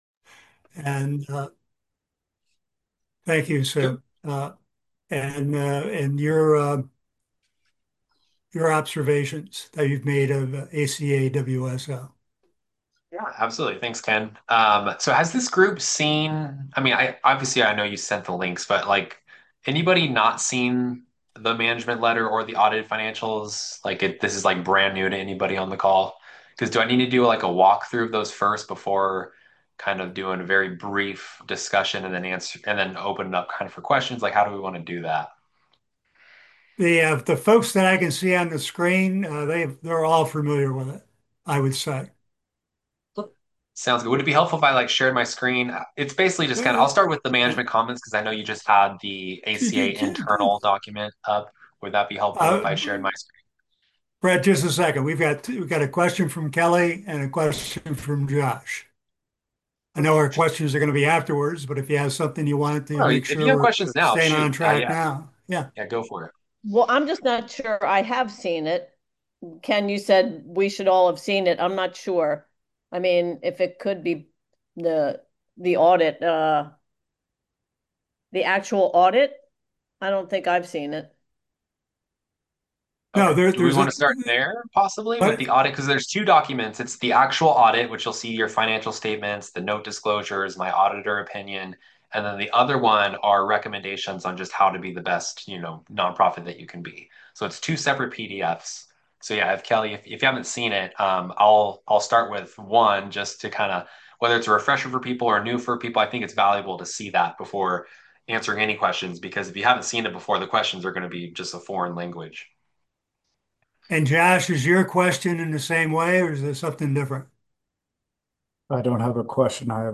Meeting with the Fellowship – the independent auditor met with the ACA Fellowship to discuss the 2024 audit of ACA WSO.
Audit-Meeting.mp3